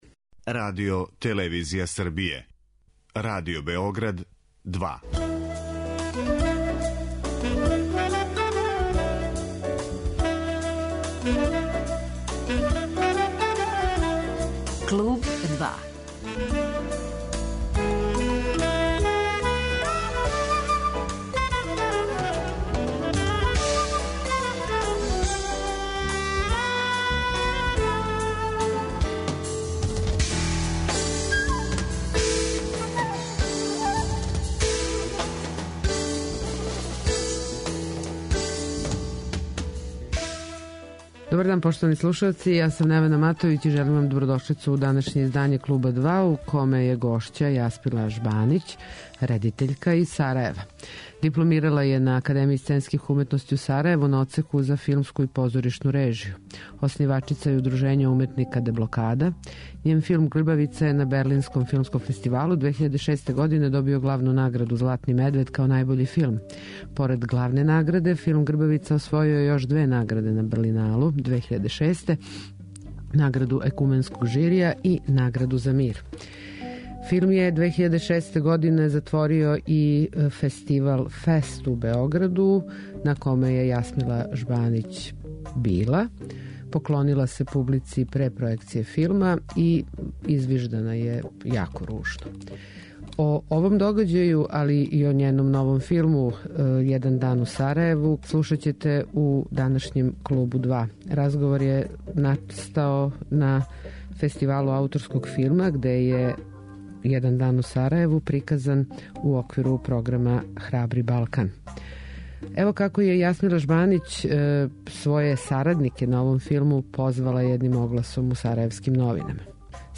Гошћа данашњег 'Клуба 2' - Јасмила Жбанић - боравила је недавно у Београду, на 21. Фестивалу ауторског филма.